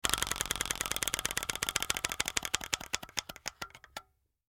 На этой странице представлены звуки колеса фортуны в разных вариациях: от классического вращения до эффектных фанфар при выигрыше.
Звук колеса фортуны в казино